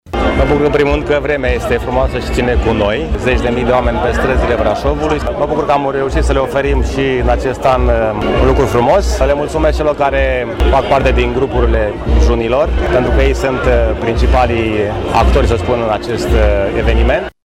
Primarul Braşovului, George Scripcaru a fost şi de această dată amfitrionul unei manifestări de excepţie, la care au luat parte şi mulţi turişti străini, din ţări precum Germania, Italia, Austria sau chiar Canada, atraşi de obiceiurile şi de tradiţia românească: